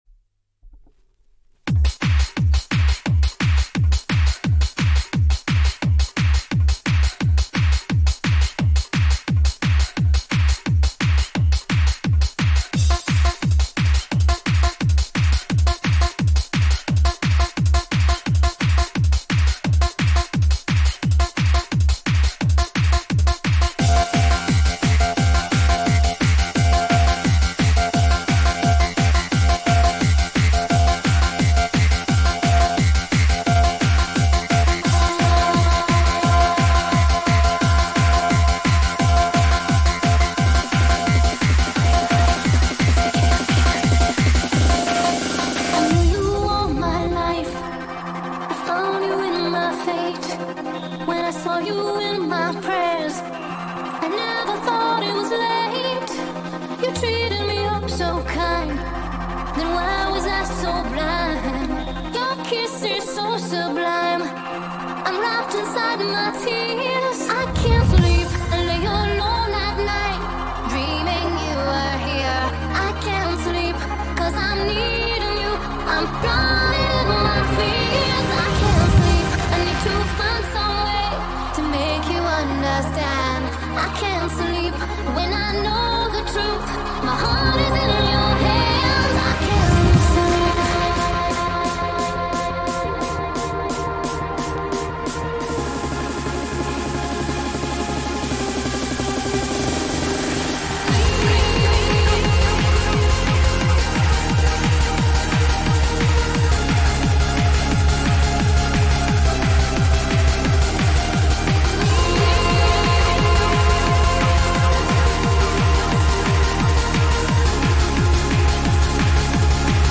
Recorded Live
Happy Hardcore
Vocals